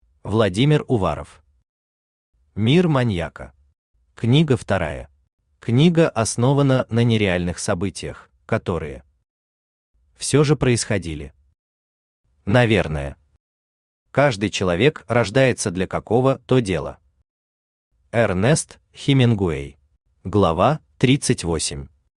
Книга вторая Автор Владимир Уваров Читает аудиокнигу Авточтец ЛитРес.